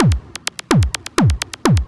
DS 127-BPM B5.wav